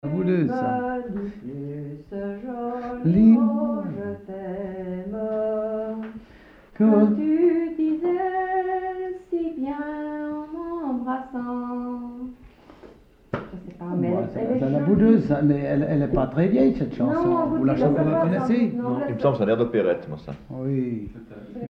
Chanson moderne
Pièce musicale inédite